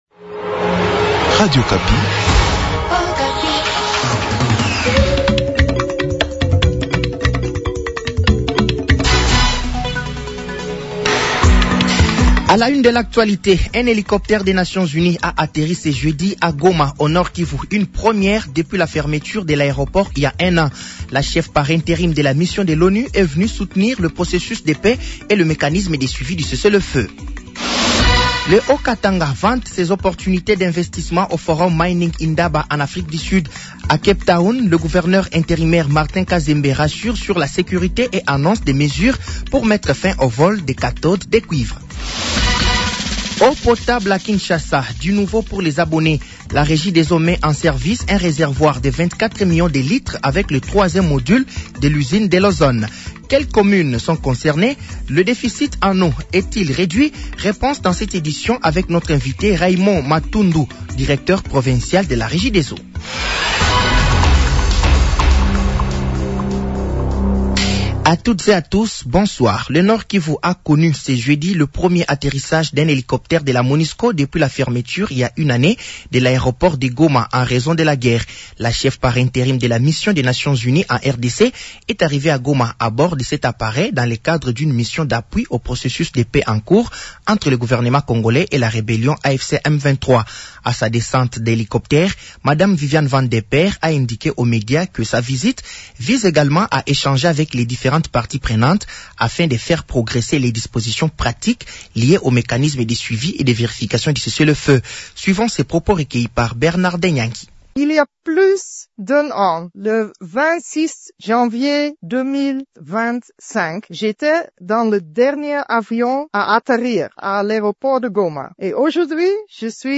Journal français de 18h de ce jeudi 12 février 2026